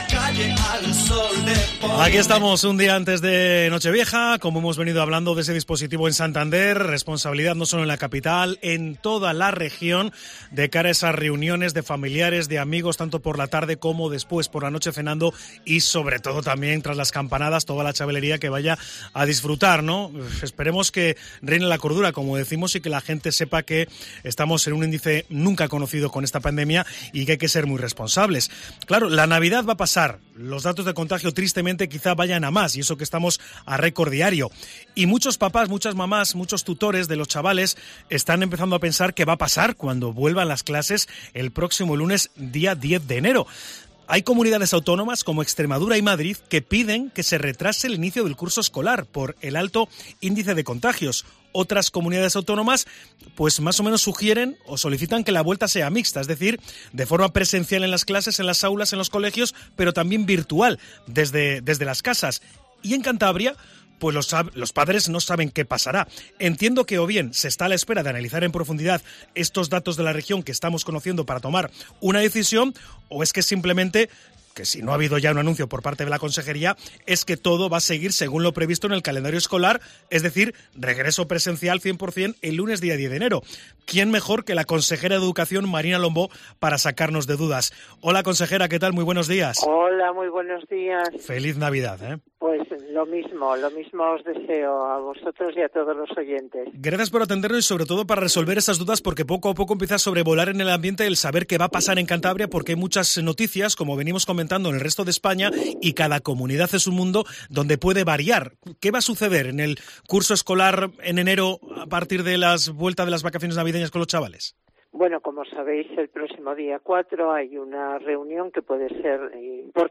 Entrevista en Cope Cantabria con la consejera de Educación, Marina Lombó